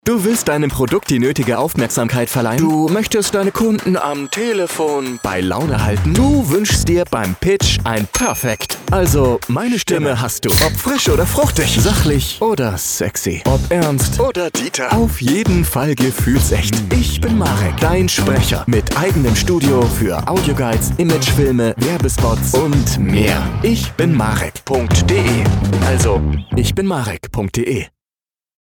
Wortakrobat und Artikulationspedant, Sprecher mit eigenem Studio für professionelle Ansprüche.
Ob frisch oder fruchtig, sachlich oder sexy, ob ernst oder dieter – auf jeden Fall gefühlsecht.